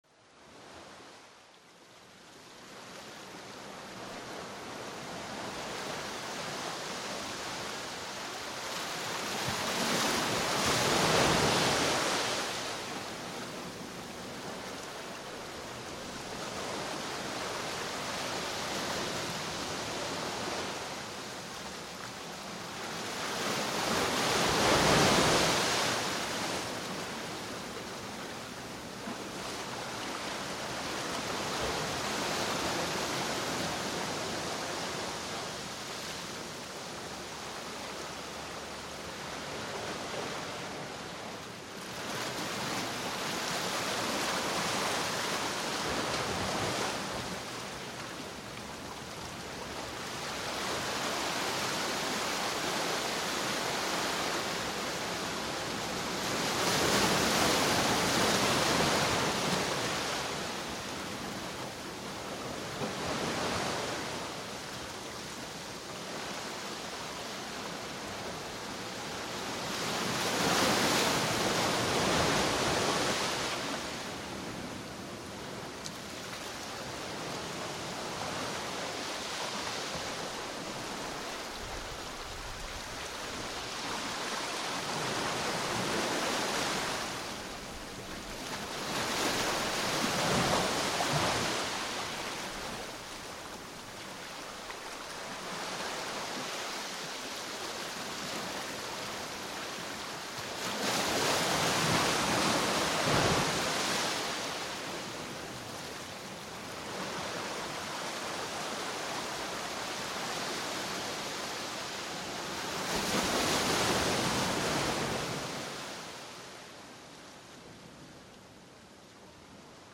Ejemplo. Sonido de las olas del mar.
Sonidos olas del mar.mp3